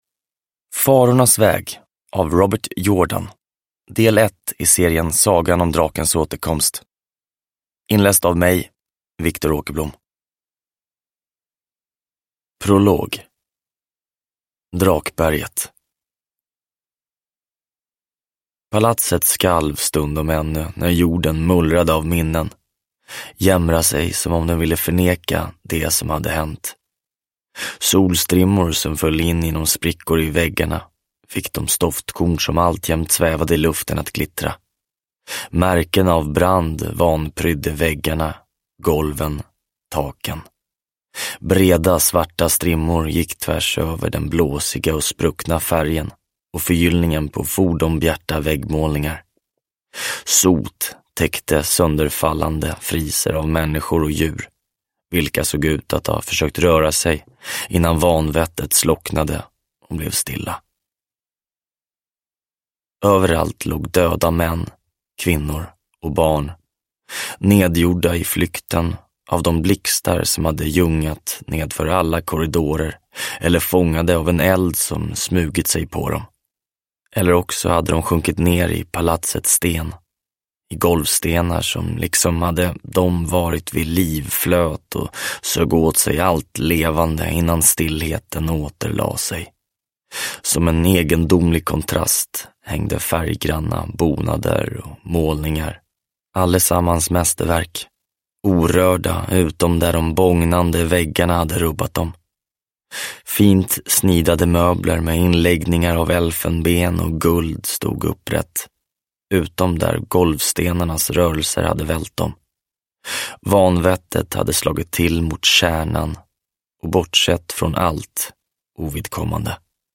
Farornas väg – Ljudbok – Laddas ner